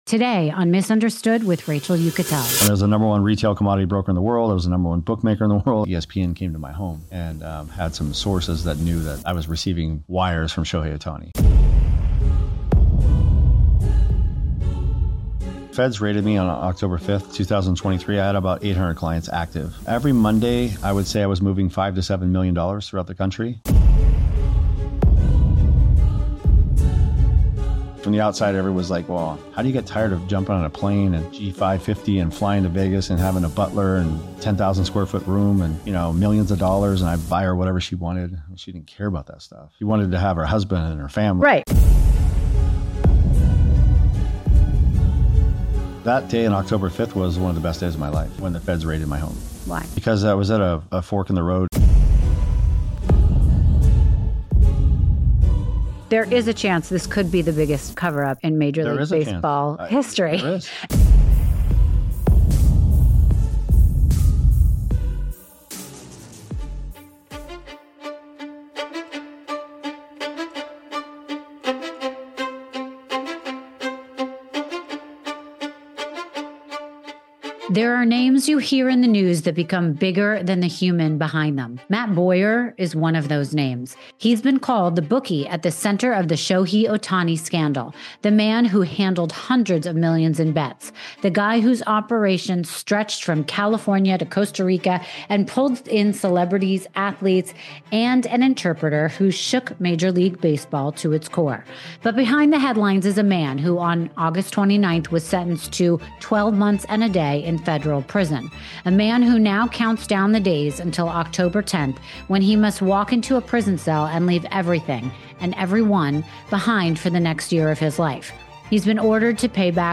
In this exclusive, face-to-face conversation—recorded in Florida, just weeks before he turns himself in—we go far deeper than any interview he’s given before. This isn’t just about the bets, the headlines, or the scandal.